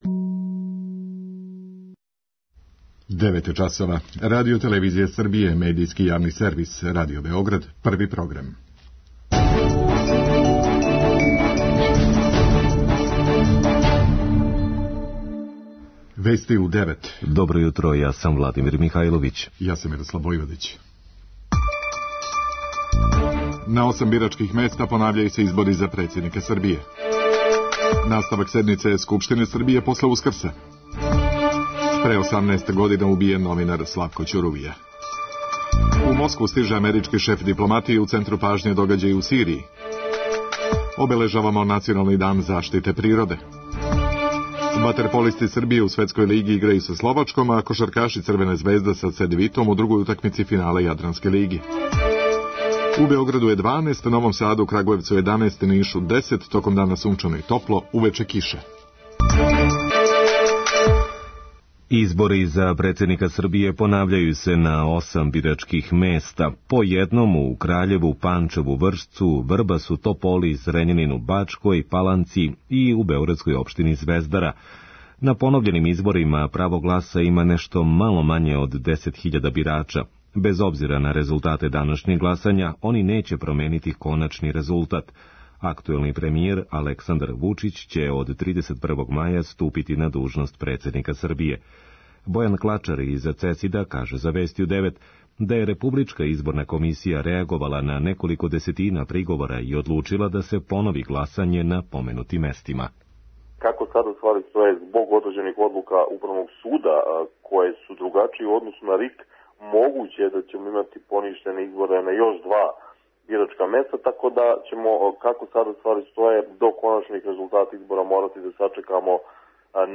преузми : 3.88 MB Вести у 9 Autor: разни аутори Преглед најважнијиx информација из земље из света.